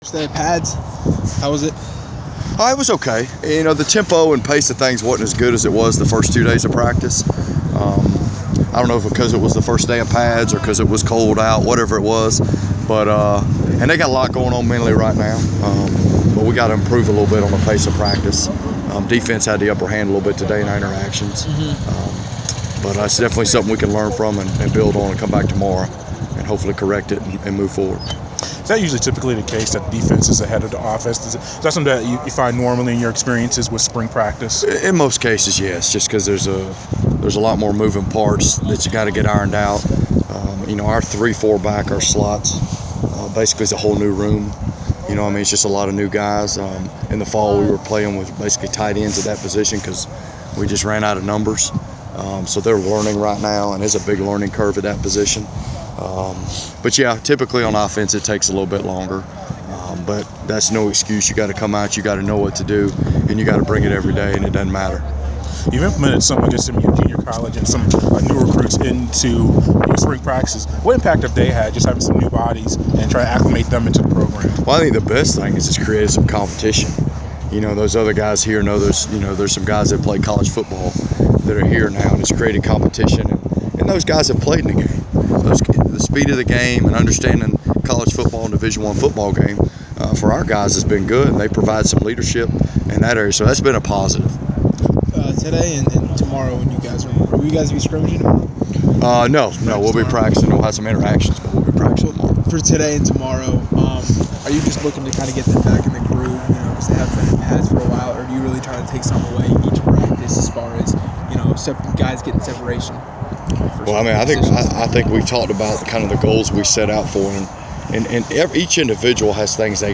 Inside the Inquirer: Interview